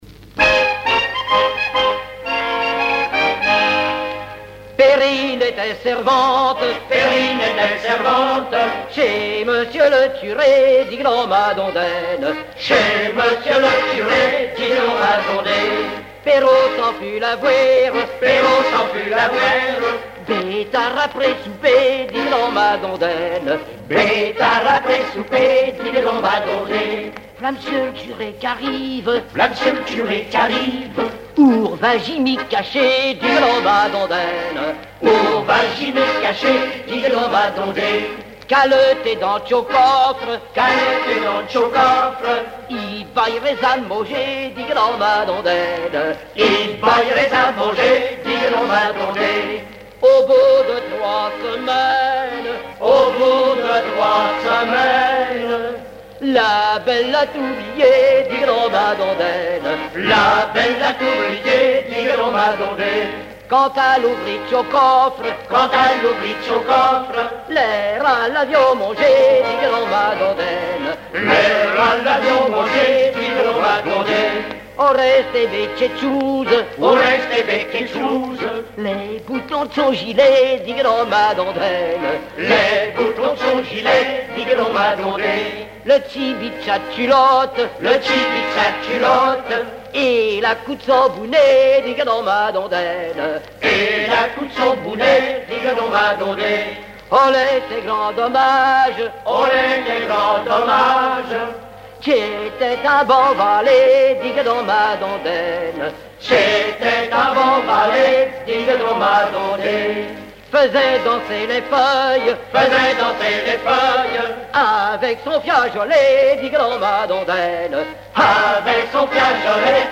Langue Patois local
Genre laisse
Catégorie Pièce musicale inédite